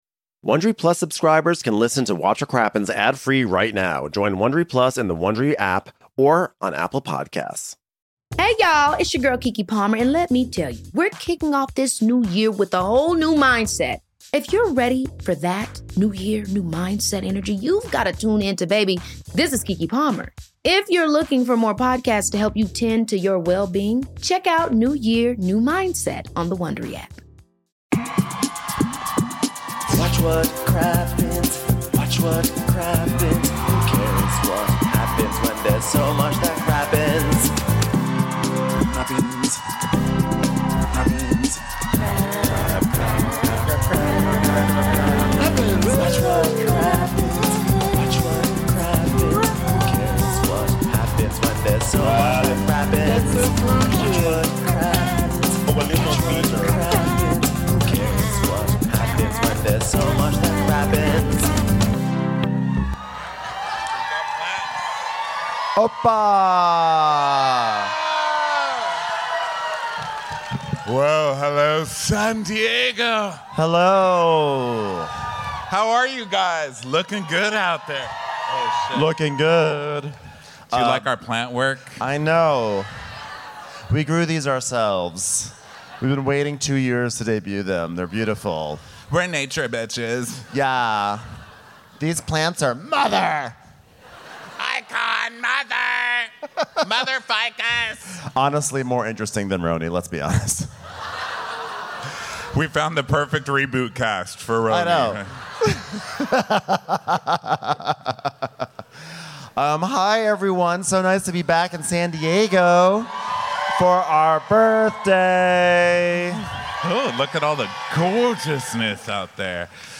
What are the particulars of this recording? #2703 RHOSLC S5E17: Against All Todds - Live from SD